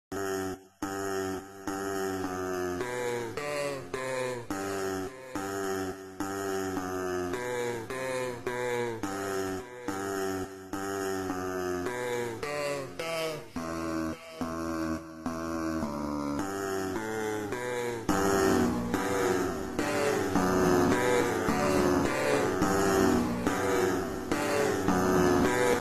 Mega Slowed Instrumental